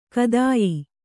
♪ kadāyi